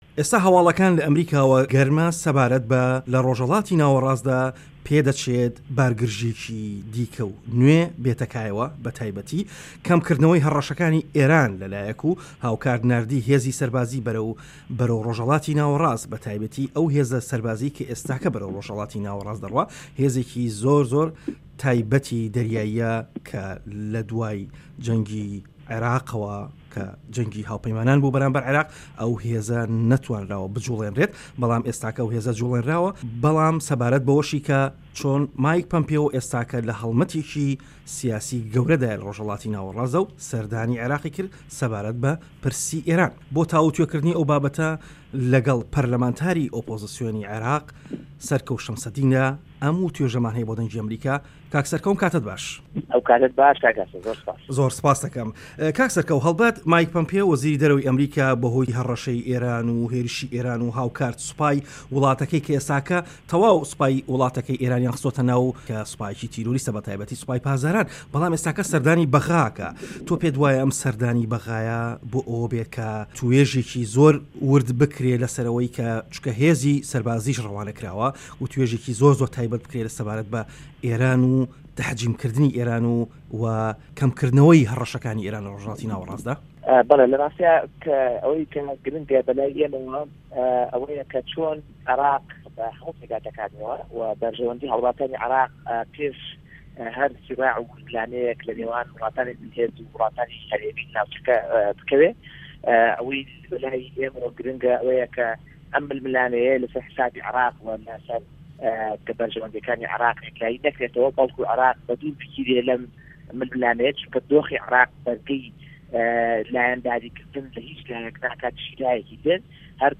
وتووێژ لەگەڵ سەرکەوت شەمسەدین